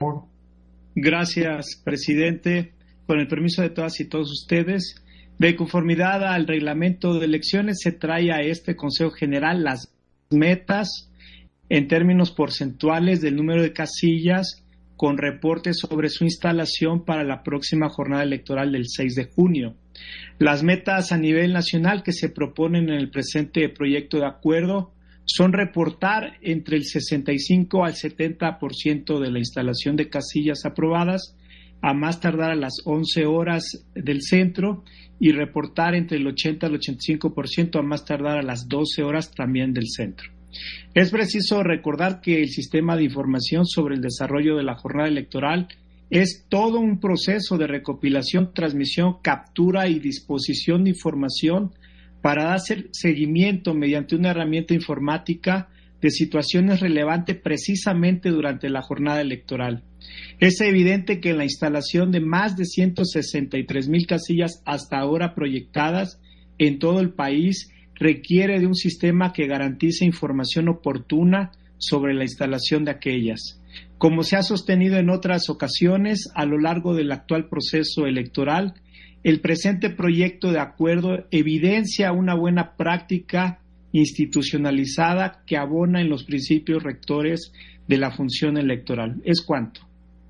250321_AUDIO_INTERVENCIÓN-CONSEJERO-JOSÉ-ROBERTO-RUIZ-PUNTO-16-SESIÓN-ORD. - Central Electoral